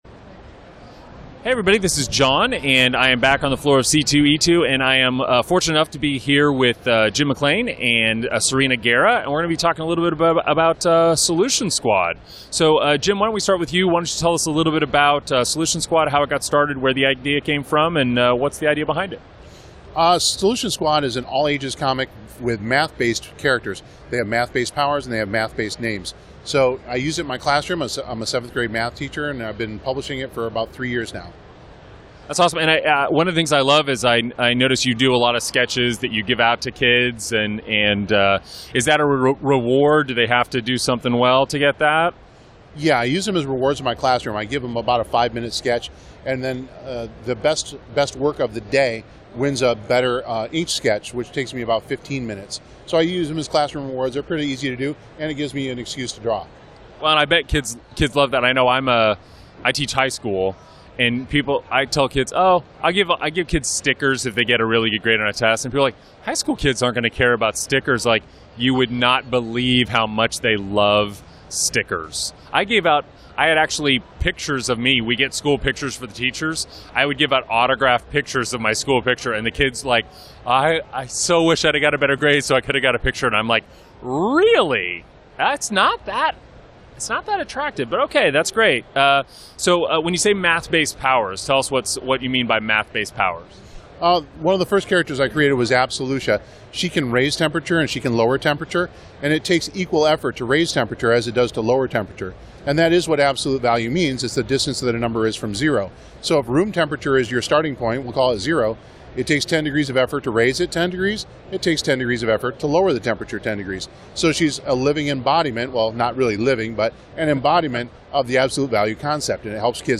Interview with the Solution Squad Creative Team from C2E2